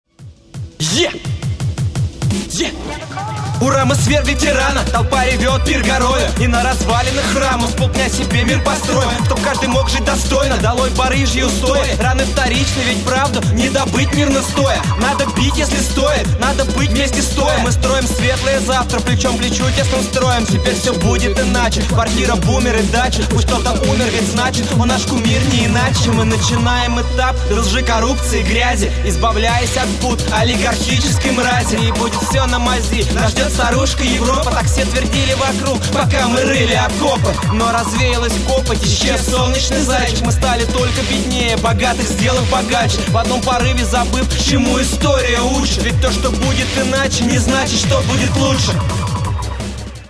Стихи хорошие, а вот подача очень слабая.
Остросоциальный текст преподнесен в слишком простой форме с ожидаемыми рифмами. Читка очень рубленая, интонации монотонны